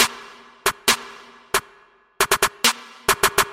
低音变奏曲链接
Tag: 126 bpm Electronic Loops Bass Loops 1.92 MB wav Key : Unknown